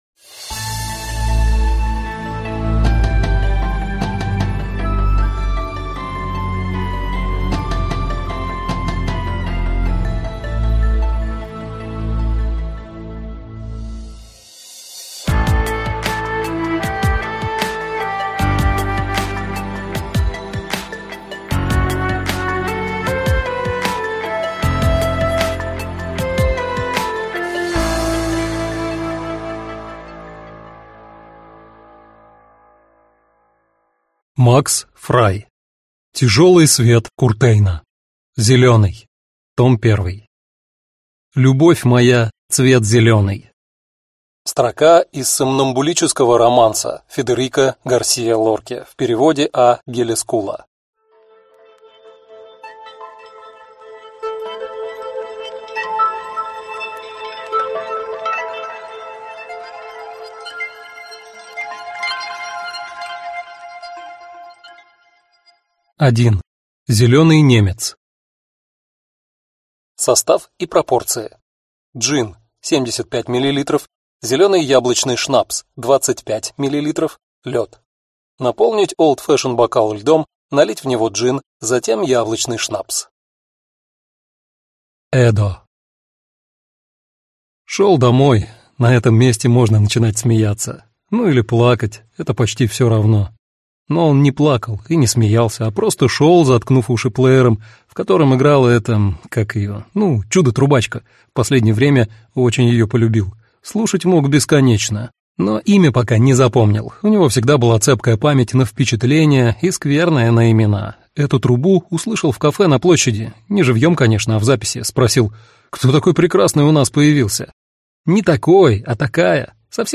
Аудиокнига Тяжелый свет Куртейна. Зеленый. Том 1 | Библиотека аудиокниг